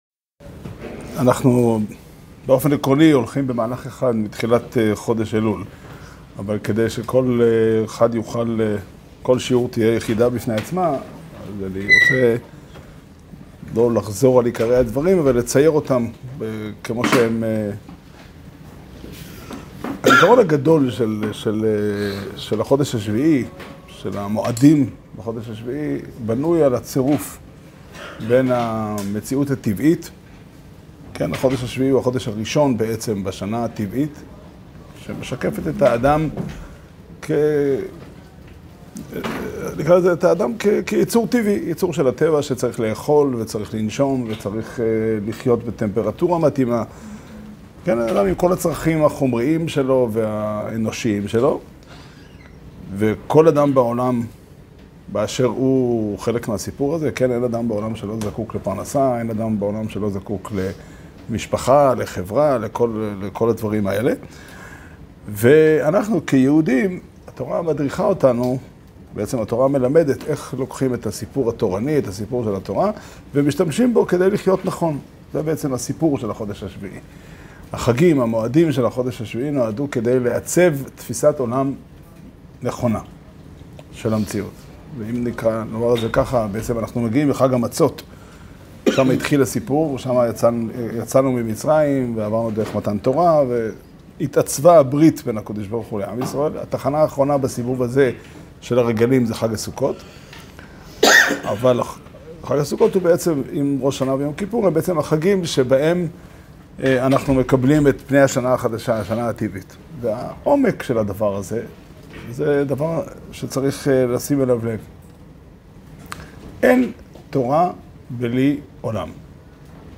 שיעור שנמסר בבית המדרש פתחי עולם בתאריך כ"ג אלול תשפ"ד